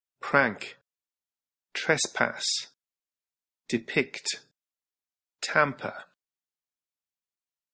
■ヒント：単語の読み上げ音声